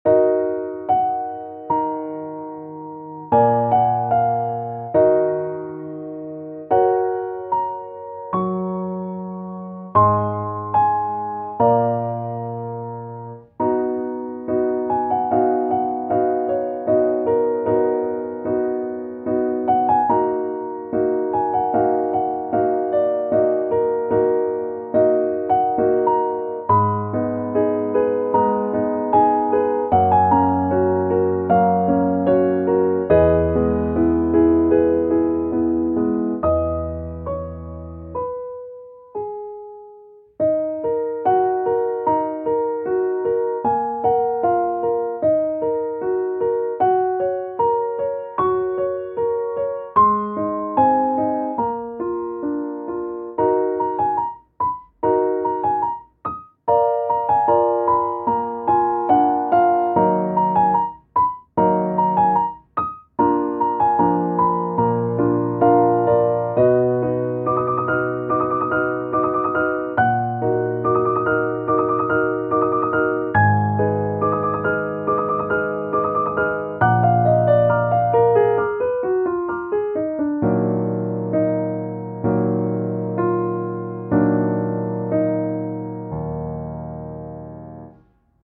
イメージ：郷愁 切ない   カテゴリ：ピアノ−暗い・しっとり